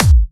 VEC3 Bassdrums Trance 47.wav